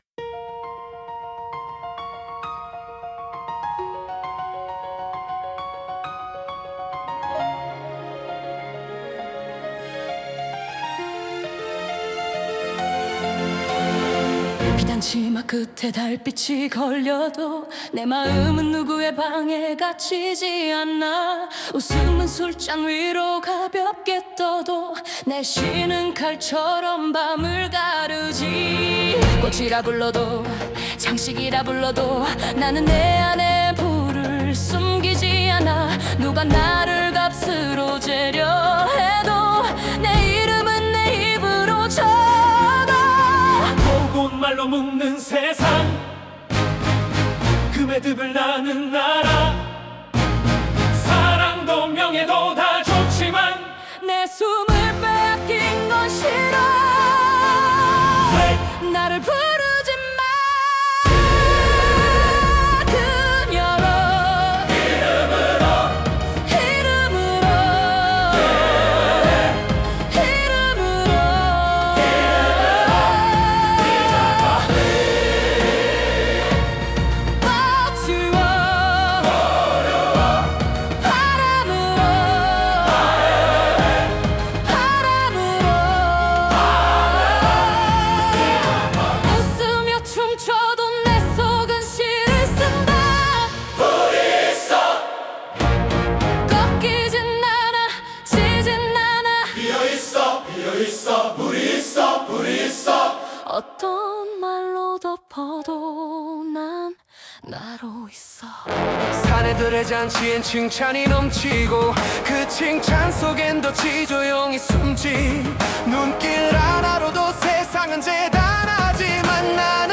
생성된 음악
다운로드 설정 정보 Scene (장면) Topic (주제) Suno 생성 가이드 (참고) Style of Music Female Vocals, Soft Voice Lyrics Structure [Meta] Language: Korean Topic: [Verse 1] (조용한 시작, 의 분위기를 묘사함) ...